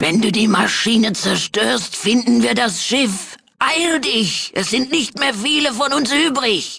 in: Fallout 2: Audiodialoge Datei : EELD6.ogg Quelltext anzeigen TimedText Versionsgeschichte Diskussion Tritt unserem Discord bei und informiere dich auf unserem Twitter-Kanal über die aktuellsten Themen rund um Fallout!